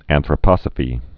(ănthrə-pŏsə-fē)